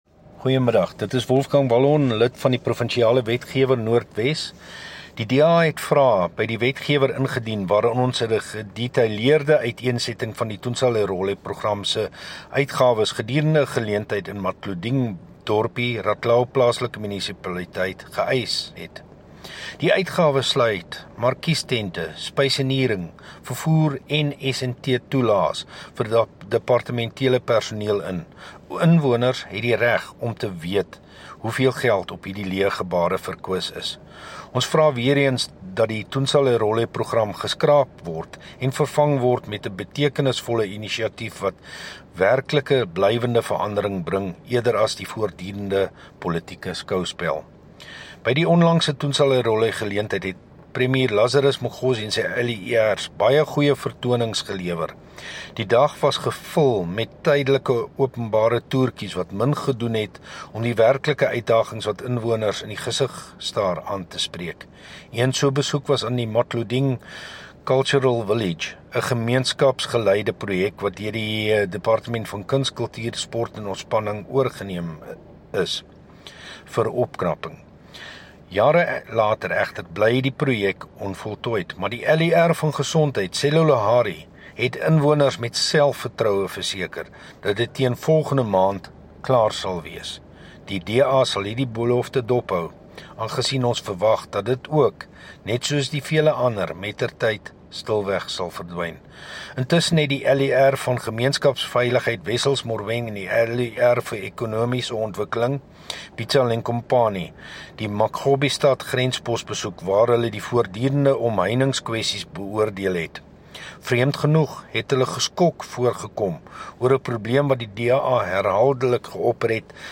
Note to Broadcasters: Please find linked soundbites in
Afrikaans by Wolfgang Wallhorn MPL.